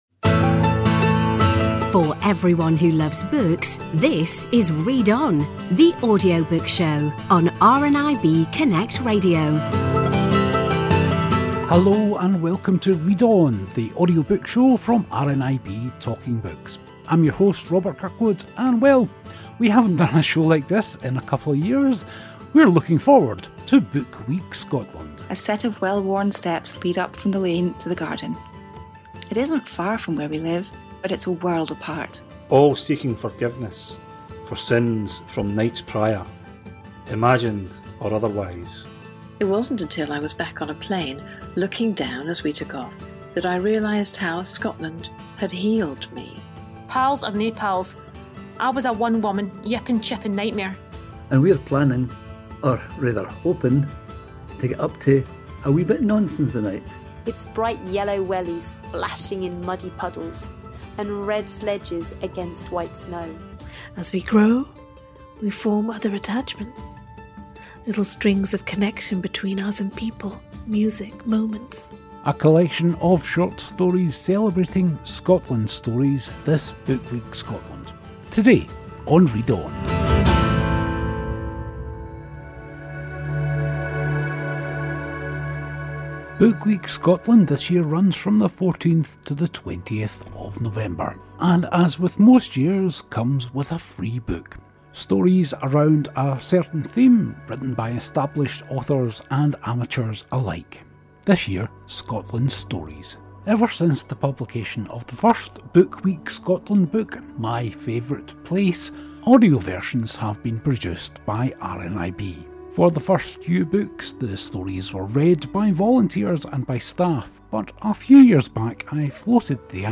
The audio version of the Book Week Scotland Book is always produced by RNIB, with many of the stories being recorded at home by the authors. Today's show is a small selection of those tales.